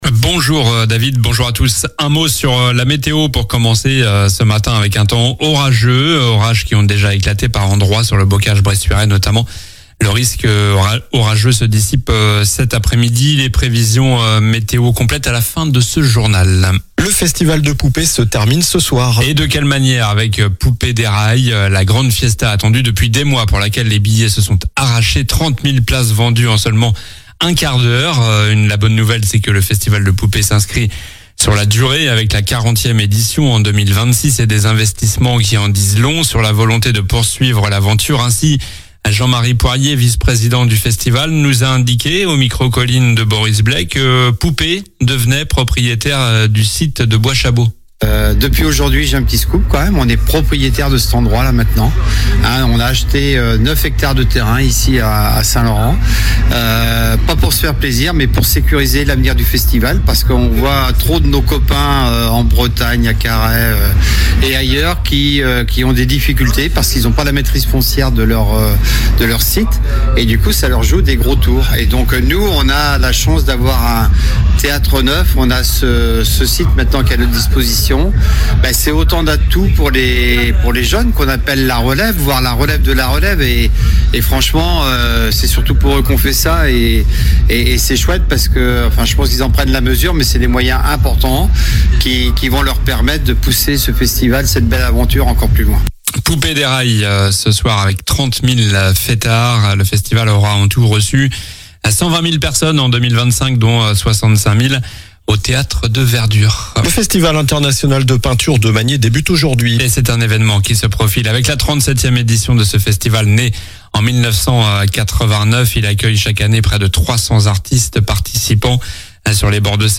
Journal du vendredi 18 juillet (matin)